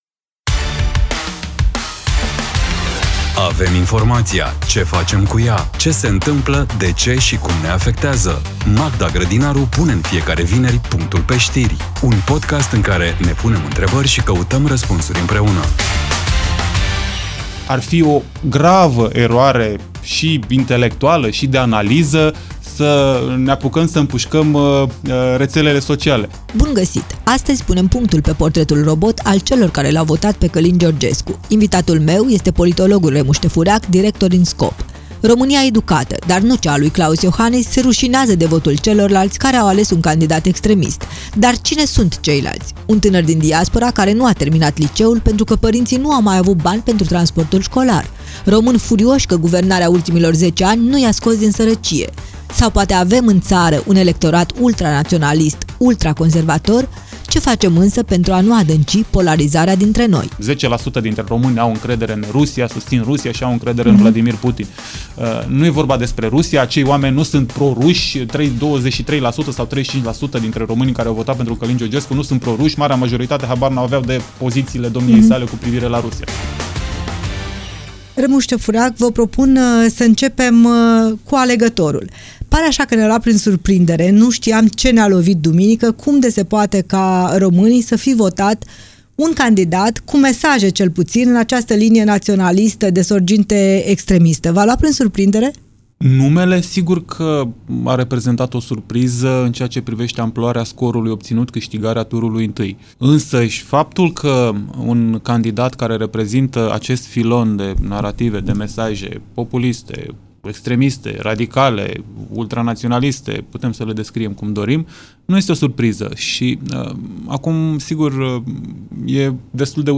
Un dialog inteligent, relaxat și necesar.